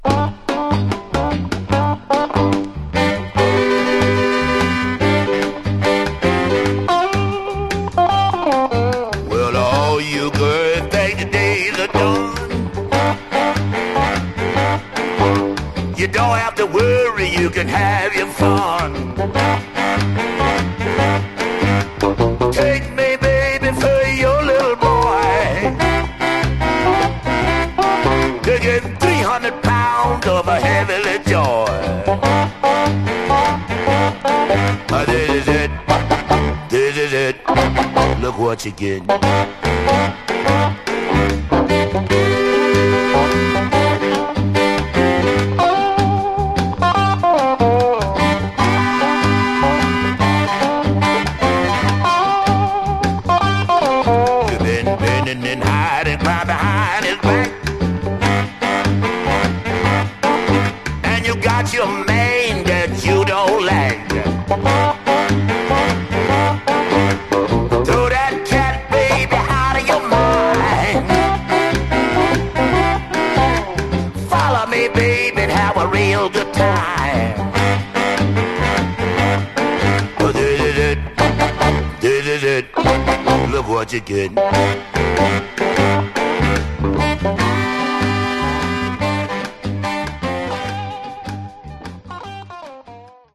Genre: Rockin' RnB